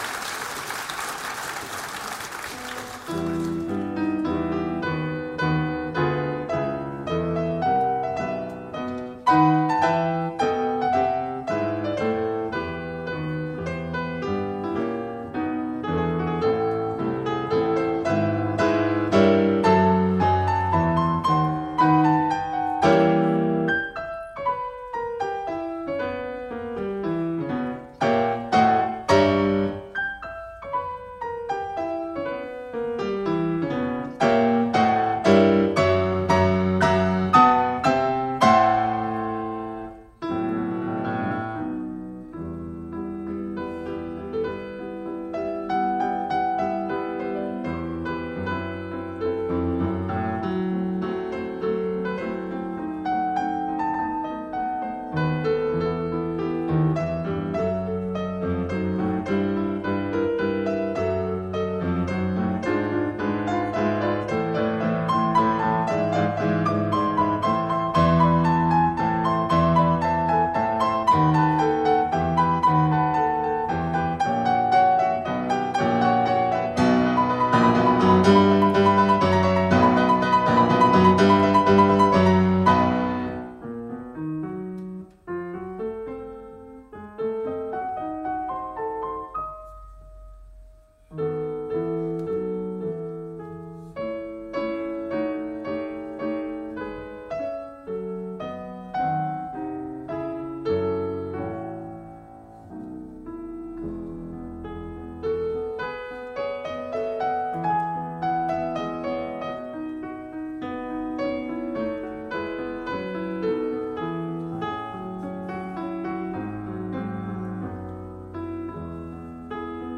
Genre: Klassik.